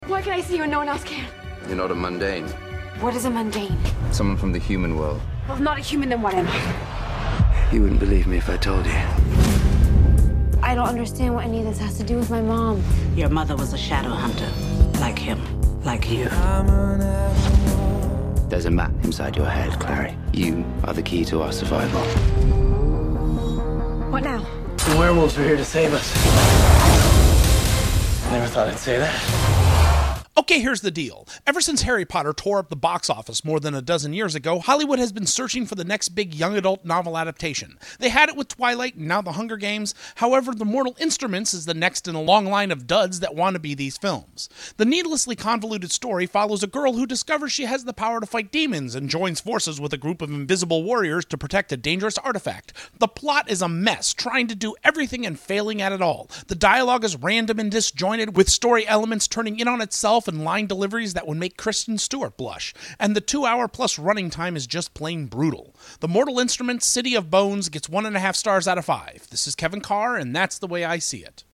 ‘The Mortal Instruments: City of Bones’ Movie Review